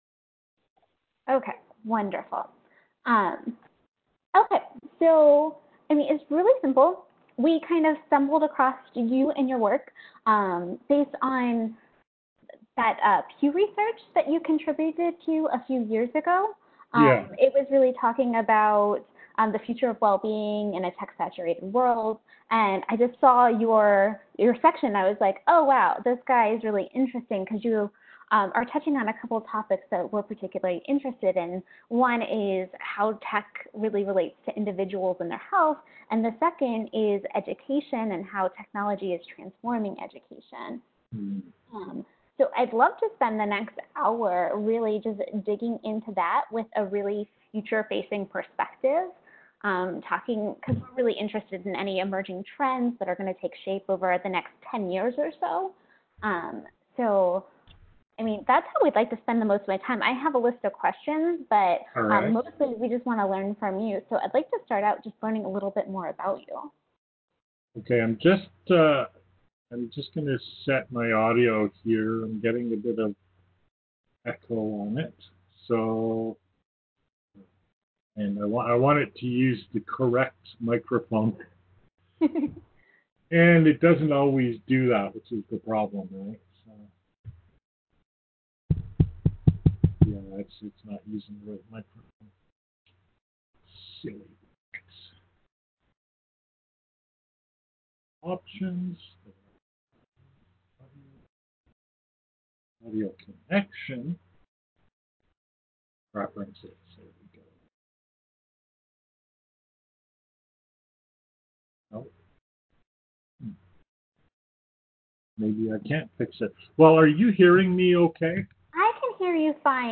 This is a conversation with some people from the health care industry in the U.S. asking about what I expect to see after the current wave of technology.